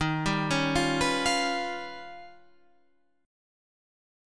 D# Augmented 9th